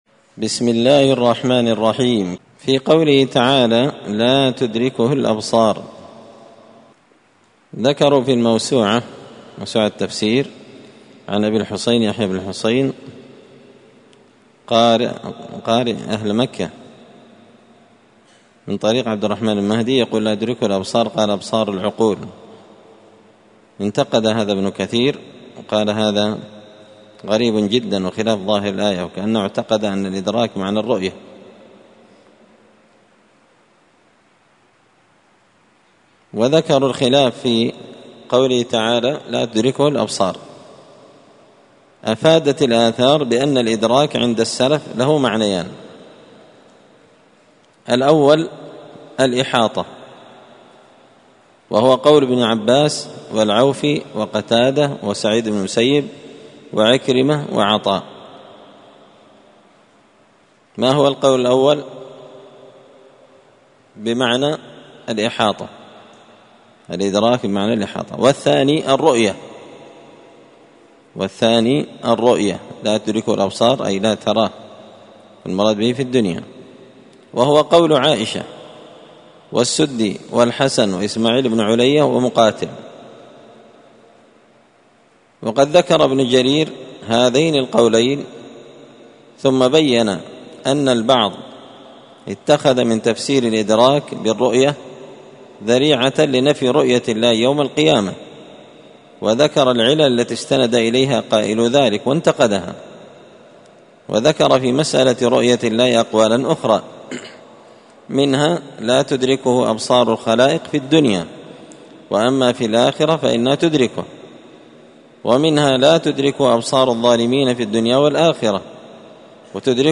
مختصر تفسير الإمام البغوي رحمه الله ـ الدرس 338 (الدرس 48 من سورة الأنعام)
مسجد الفرقان قشن_المهرة_اليمن